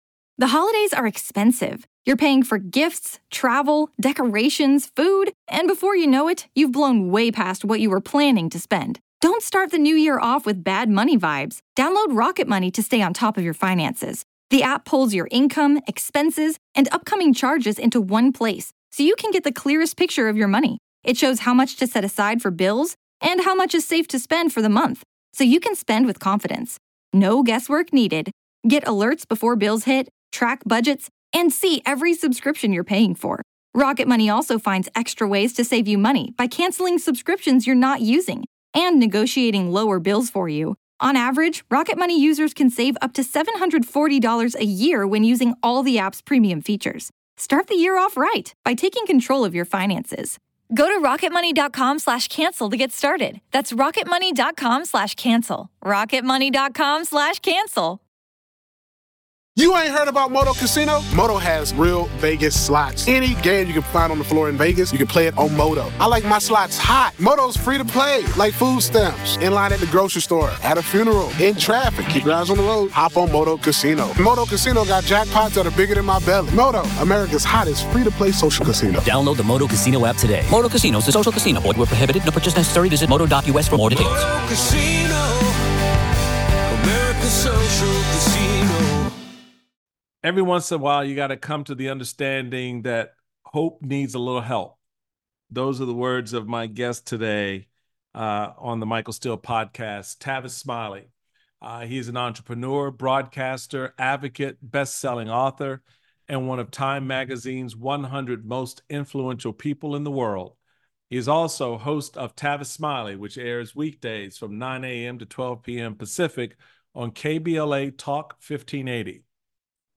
Michael Steele speaks with Tavis Smiley, entrepreneur, broadcaster, best-selling author, and host of Tavis Smiley, airing weekdays from 9am-12pmPT on KBLA Talk 1580. The pair discuss the impact of talk radio on politics and the importance of Black and independent radio.